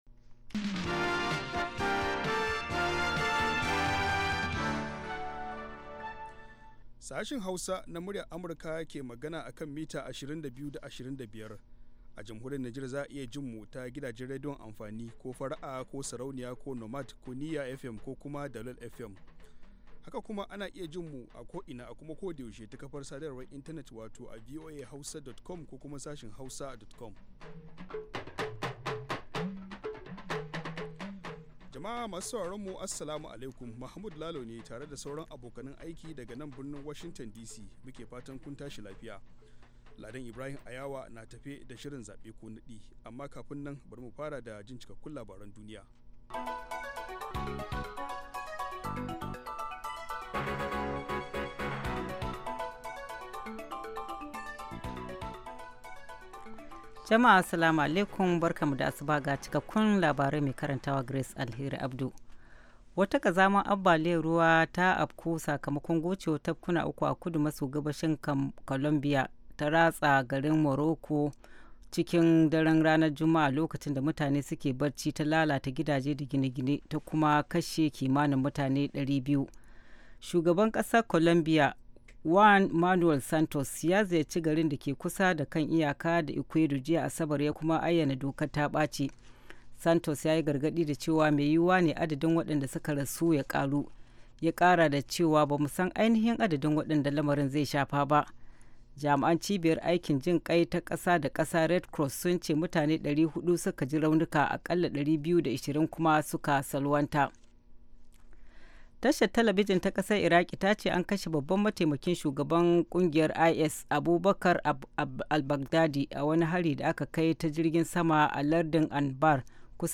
Kullum da karfe 6 na safe agogon Najeriya da Nijar muna gabatar da labarai da rahotanni da dumi-duminsu, sannan mu na gabatar da wasu shirye-shirye kamar Noma da Lafiya Uwar Jiki.